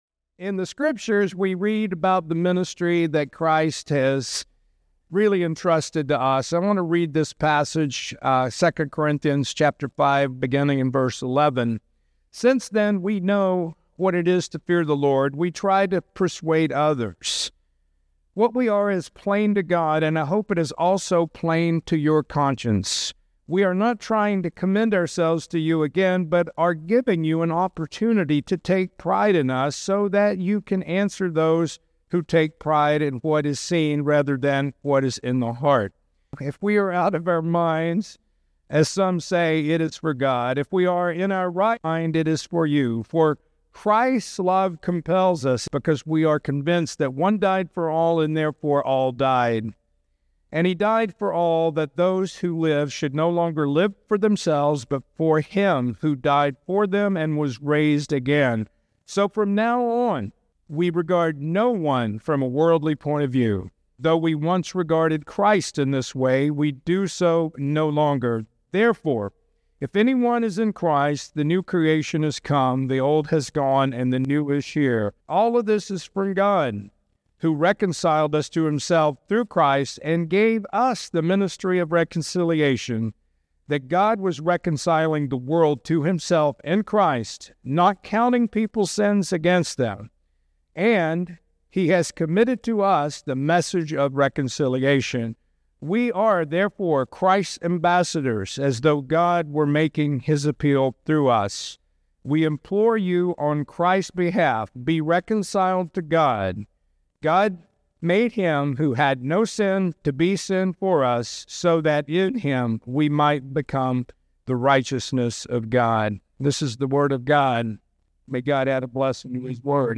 Surrounded by U.S. pastors at the Home Office, the ceremony honored his decades of service and leadership.